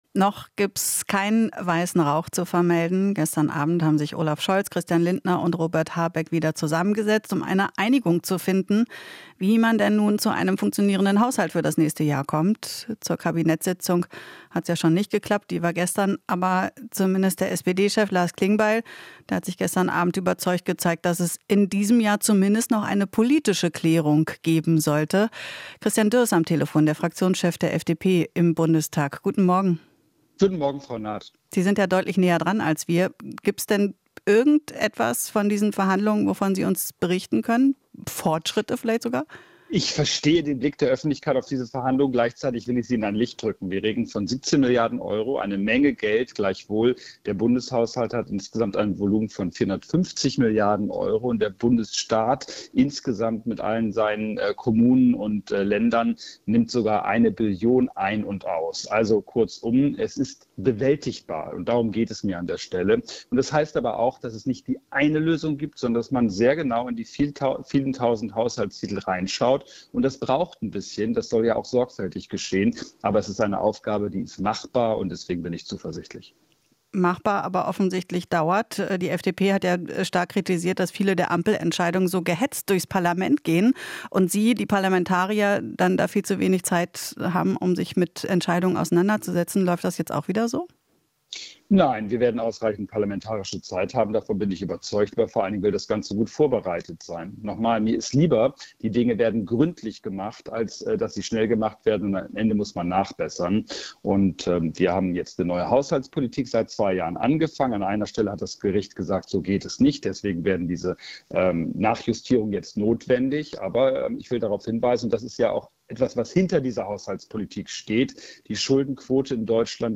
Interview - Dürr (FDP) zur Haushaltskrise: "Es ist bewältigbar"